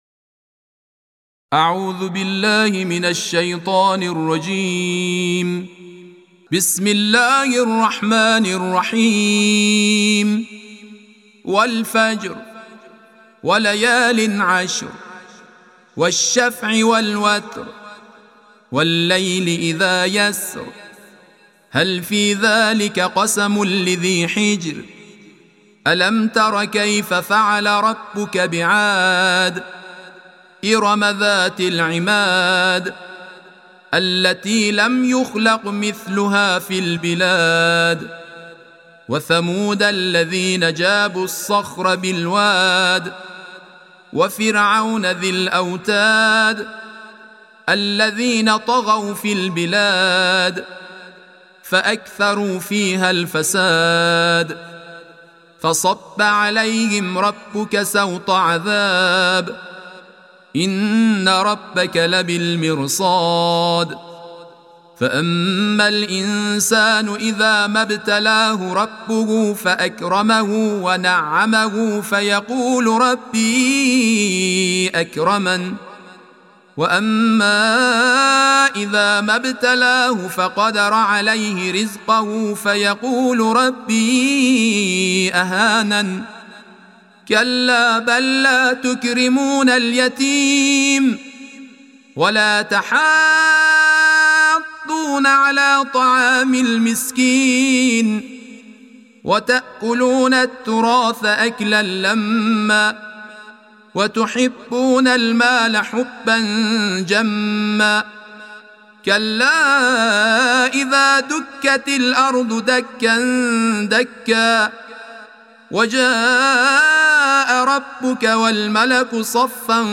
تلاوت ترتیل